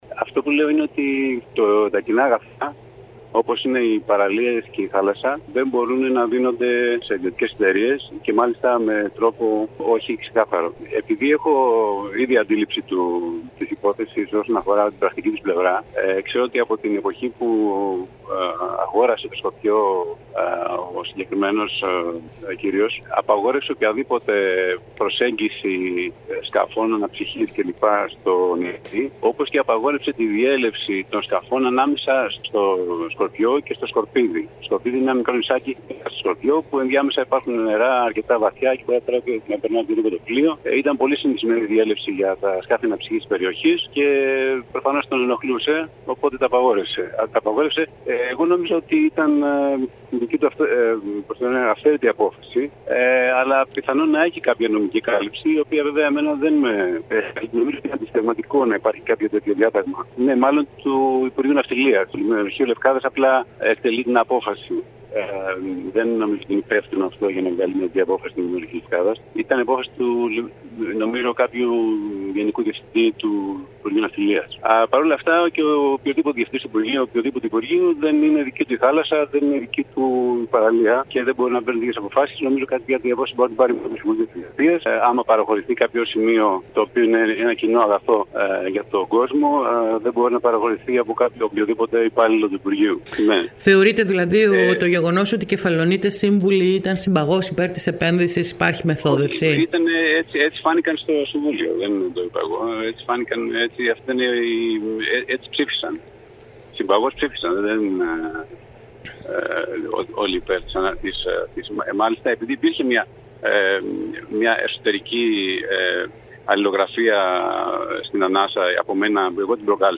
Ο κος Μπουκας προανήγγειλε την αποχώρηση του από την πλειοψηφούσα παράταξη του περιφερειακού συμβουλίου, με αναρτηση του στο Φ/Β. Μιλώντας σήμερα στην ΕΡΤ Κέρκυρας δήλωσε ότι έχει κατα τη γνώμη του ευθύνες και ο Περιφερειάρχης για την απόφαση αυτή.